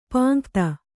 ♪ pāŋkta